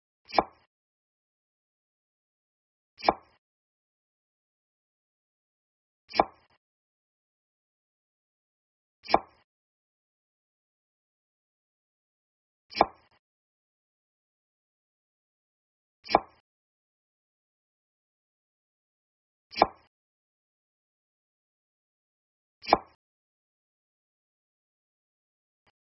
Tiếng Dao Cắt, Thái… trên thớt (chậm rãi, từ từ…)
Thể loại: Tiếng ăn uống
Description: Tiếng dao cắt, thái trên thớt là âm thanh đầy hấp dẫn và đặc biệt khi được thực hiện chậm rãi, từ từ.
tieng-dao-cat-thai-tren-thot-cham-rai-tu-tu-www_tiengdong_com.mp3